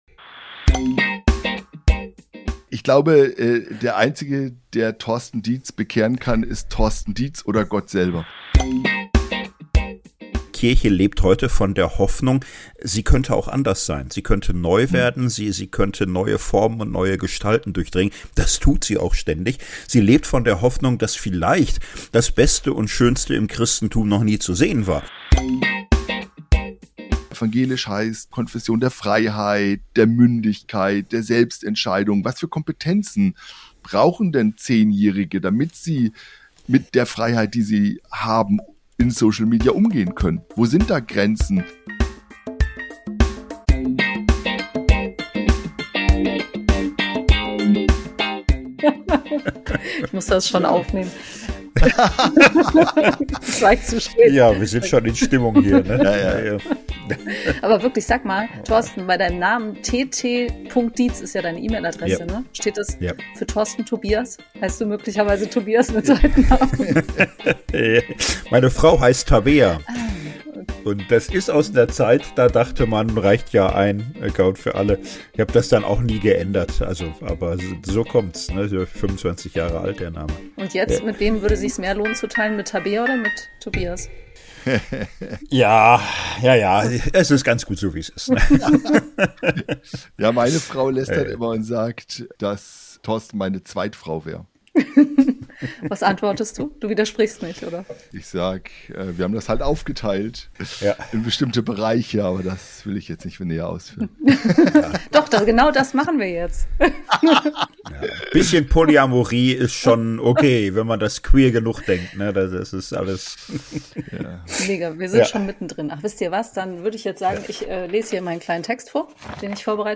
Social Media für Glaube, Spiritualität und Kirche - das ist der yeet-Podcast: yeet-Redakteur*innen befragen Expert*innen und Influencer*innen und begeben sich auf die Suche nach den großen und kleinen Perspektiven auf die digitalen Kirchen-Räume und Welten in den Sozialen Medien.